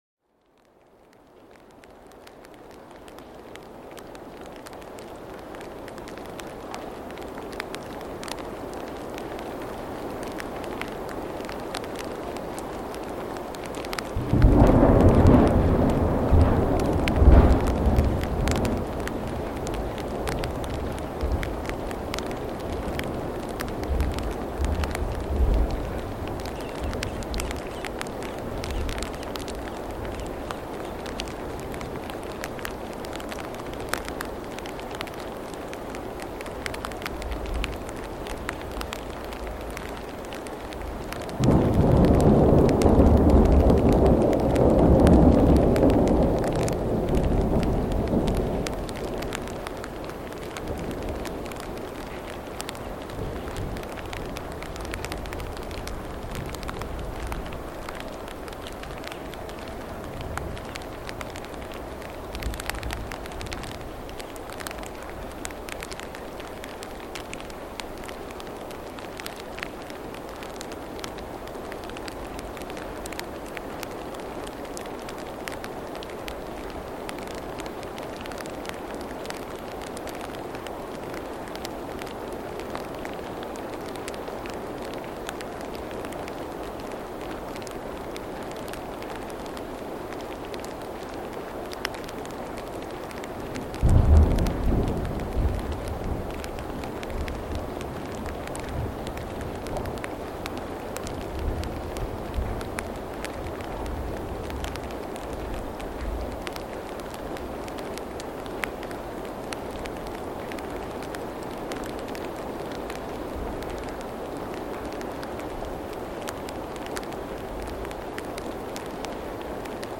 Lightning Storm Drift—Rain & Thunder for Deep Relaxation